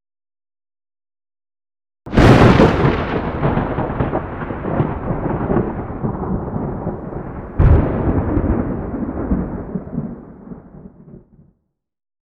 thundernew2.ogg